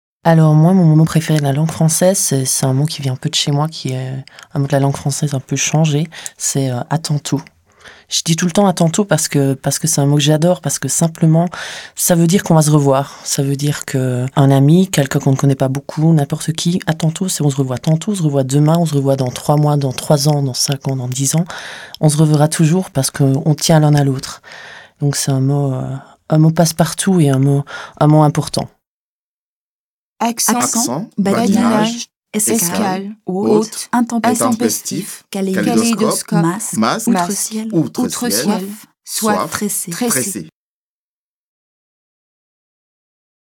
avec jingle